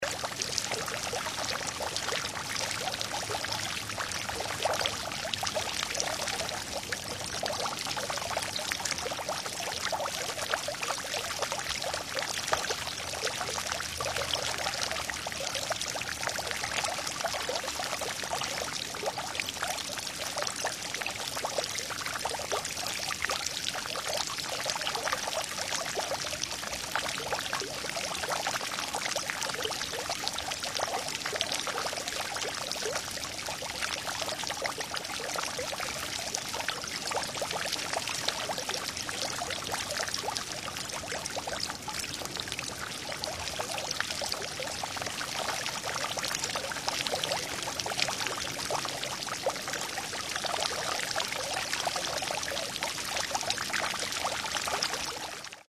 Звуки фонтана
Капли воды из фонтана